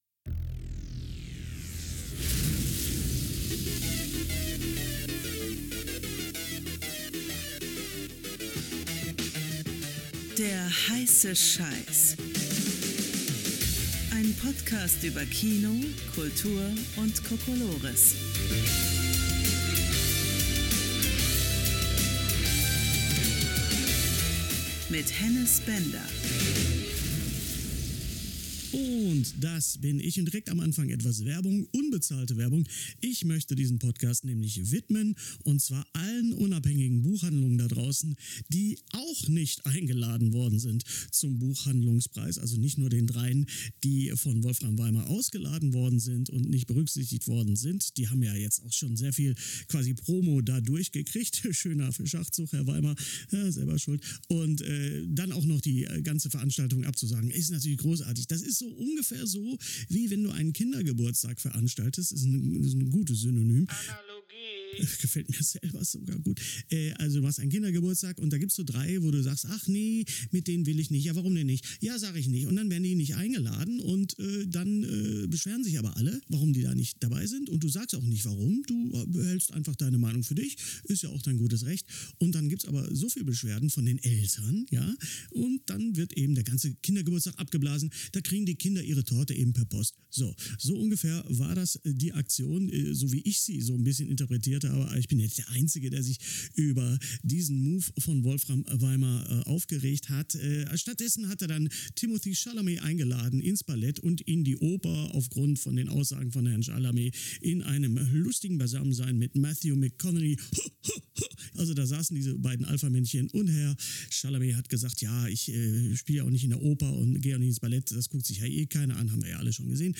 Diesmal senden wir Live on Tape aus den heiligen Backstage-Hallen des alt-ehrwürdigen und legendären KOMÖDCHEN, dem Traditions-Kabarett-Theater mitten in der Düsseldorfer Altstadt.